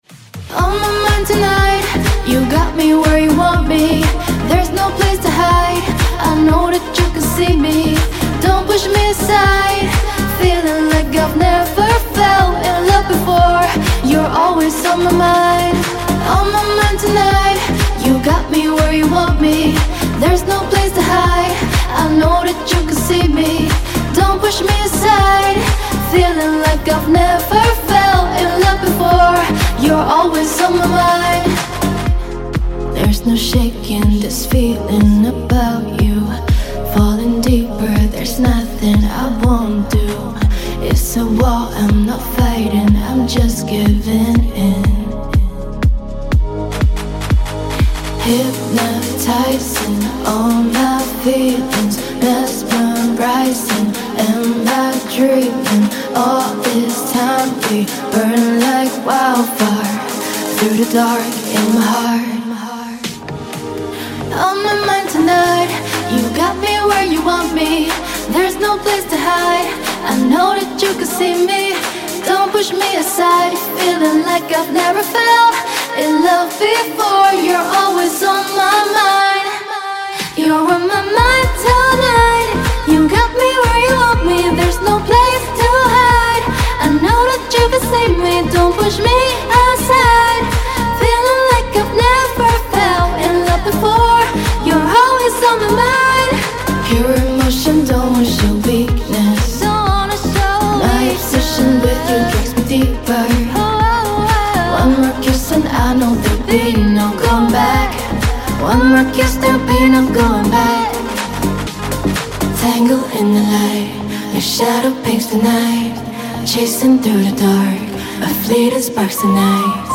2026-01-14 EDM · Electro Pop · Pop 157 推广
该采样包包含 2 首优美的清唱人声，并已拆分为干声分轨，为您提供最大的创作自由度和灵活性。
分轨和分割：您将收到每首清唱人声的独立分轨，其中包括干声主歌、副歌、主旋律、和声、人声循环和即兴演唱。
高品质录音：经过专业录制和混音，确保最高的音质，让您的作品达到电台播放标准。